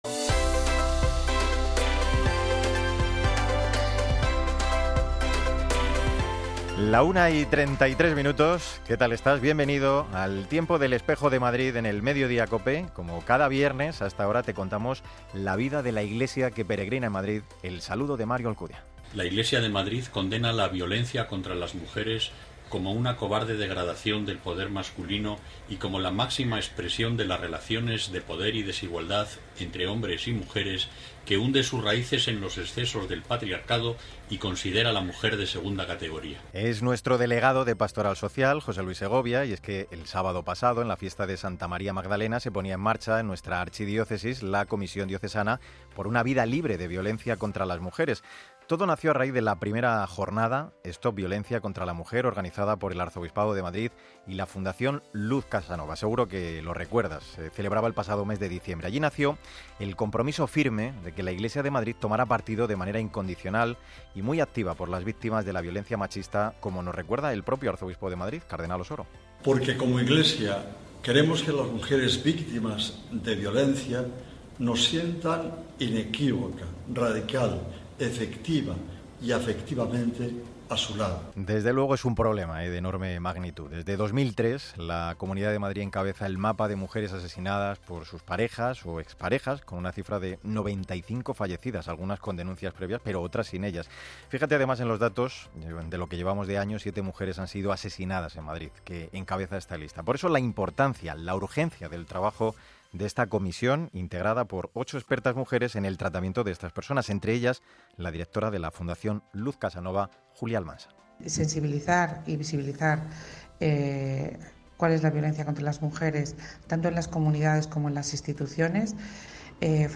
Emitido en El Espejo de Madrid, de la COPE, el 28 de julio de 2017.